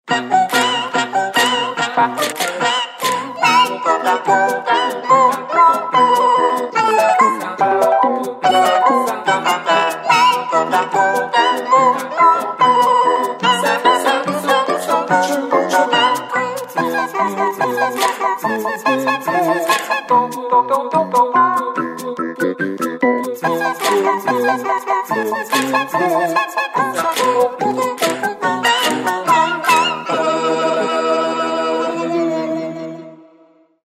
• Качество: 128, Stereo
без слов